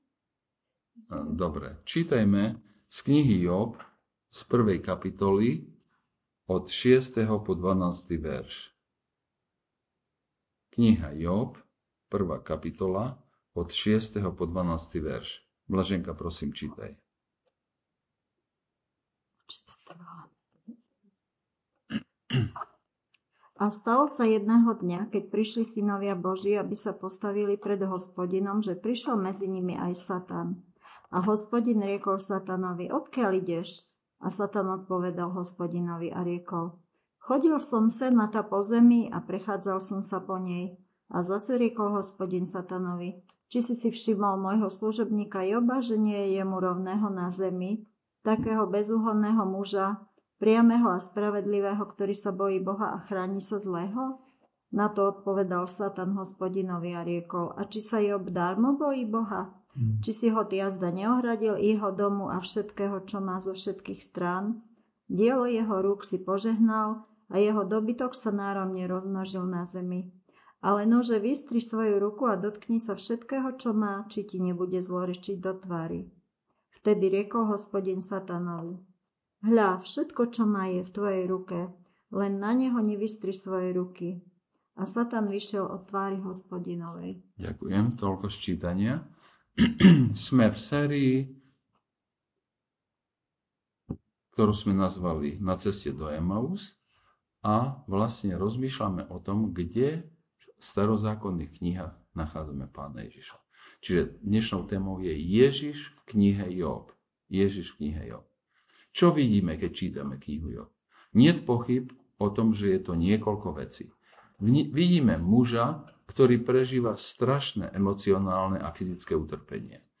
Vyučovanie - KOŠICE BAPTIST